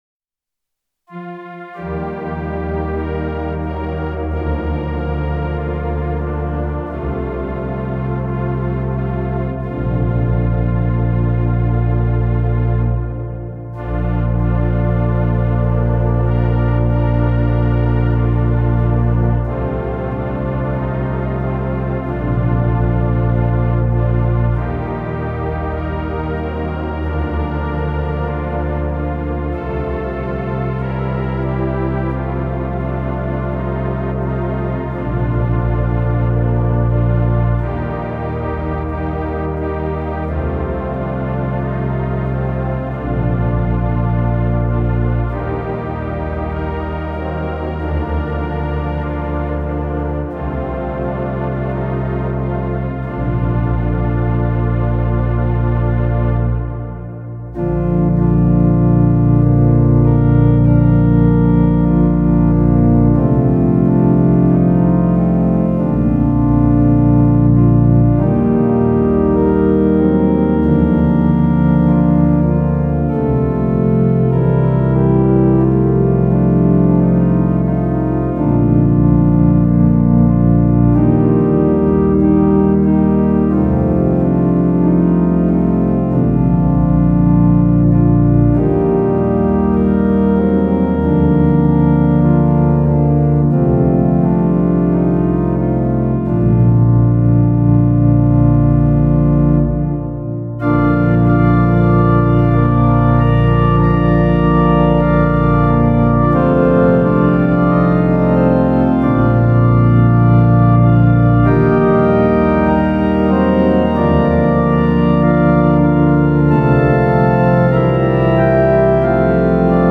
Instrument: Viscount Cantorum Duo Plus
hymn organ pipeorgan